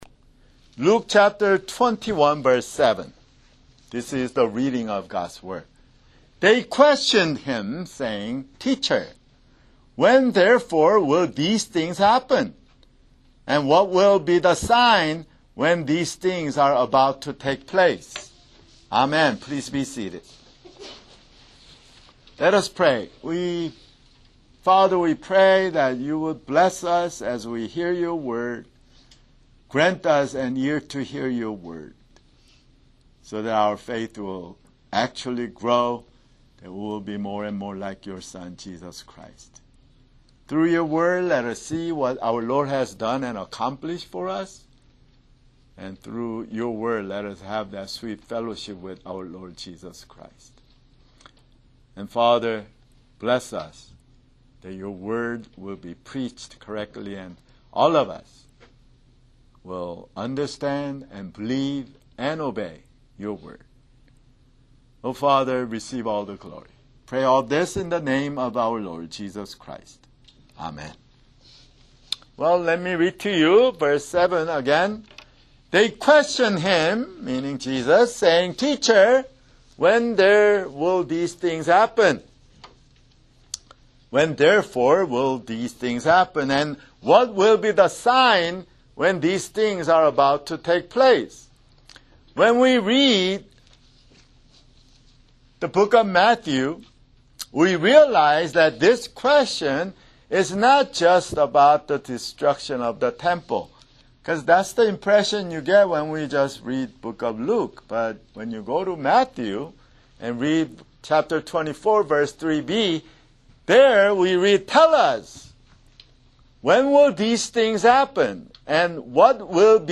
[Sermon] Luke (139)